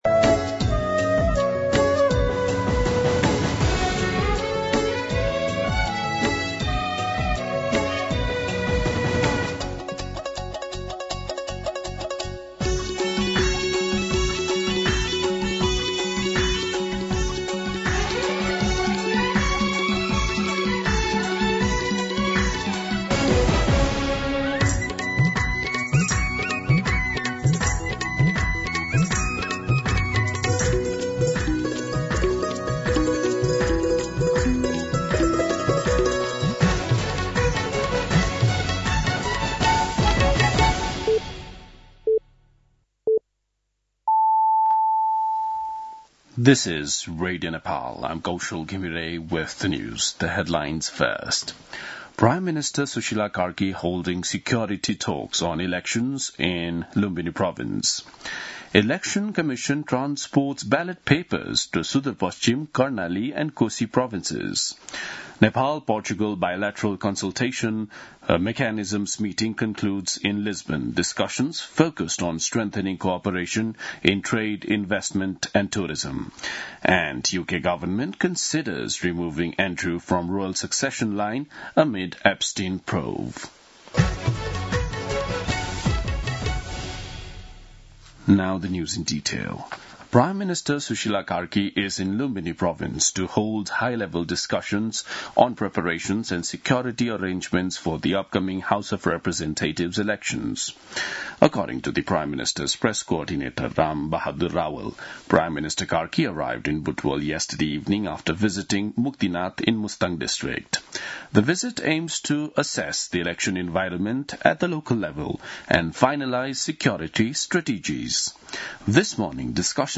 दिउँसो २ बजेको अङ्ग्रेजी समाचार : ९ फागुन , २०८२
2-pm-English-News-1.mp3